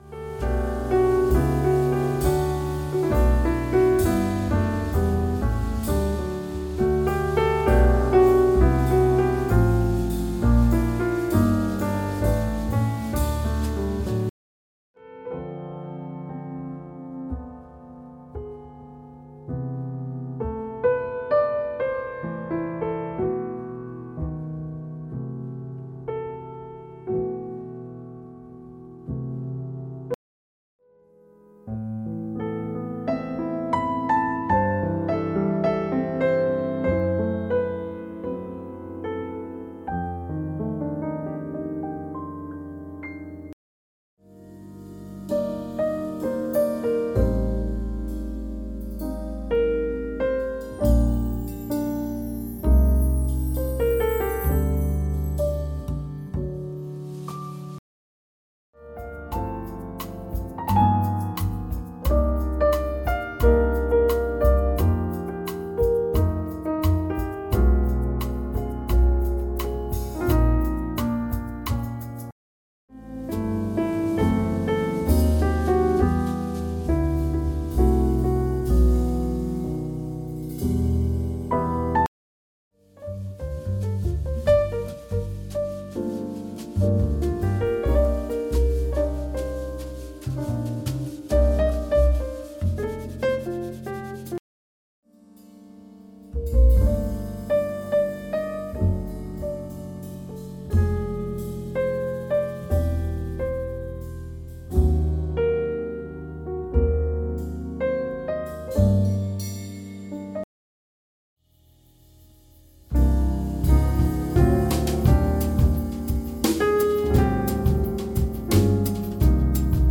drums
percussie